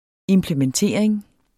Udtale [ emplemənˈteˀɐ̯eŋ ]